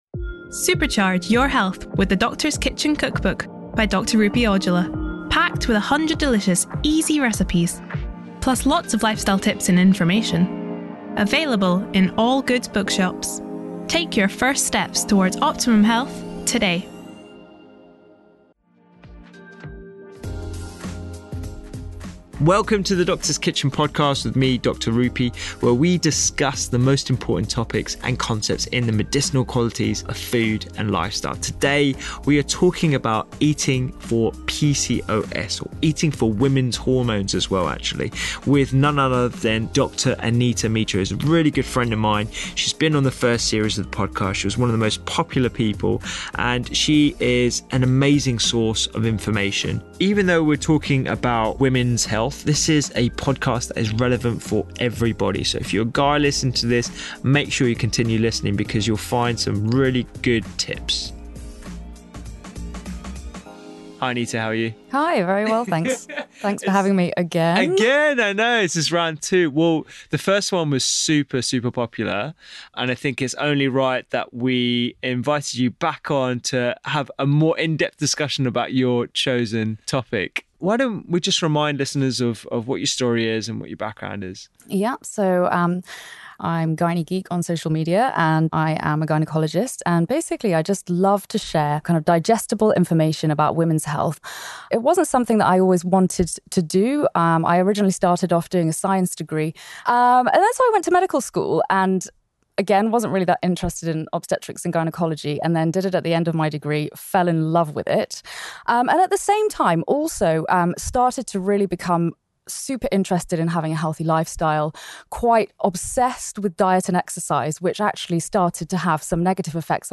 We have a fascinating chat about yoga for menstrual problems as well as over training and its pitfalls.